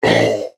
khanat-sounds-sources/_stock/sound_library/animals/ogre/ogre4.wav at main
ogre4.wav